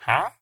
Sound / Minecraft / mob / villager / haggle1.ogg
haggle1.ogg